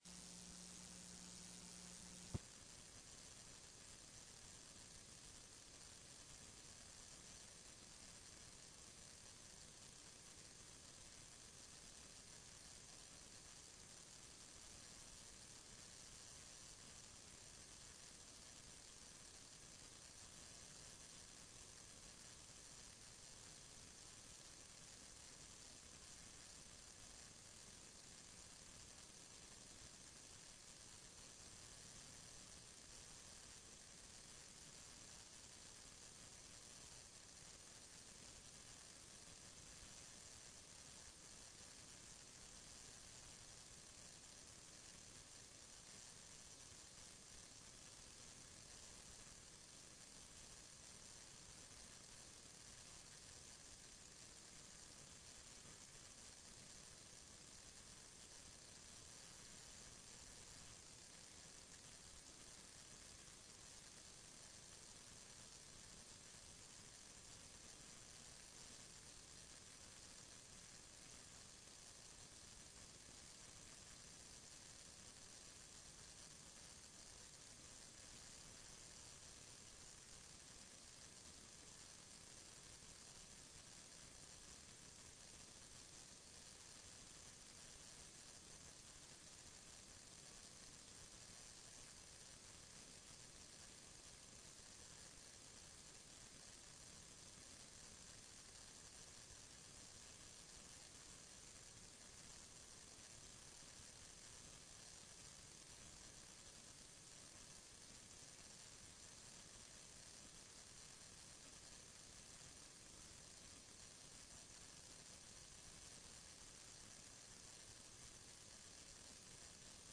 TRE-ES - Áudio da sessão 17.11